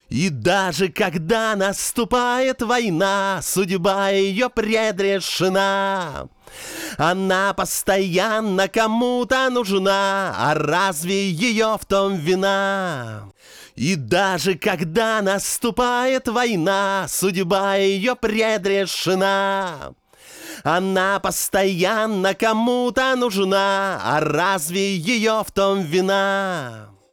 первая фраза сделана по типу 2-ой.
Но лента динамичней и поярче будет.